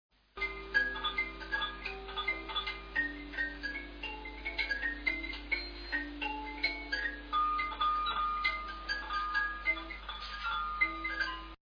Sanzas simples, calebasse, à bourdon, kalimbas
LA SANZA
sanza.mp3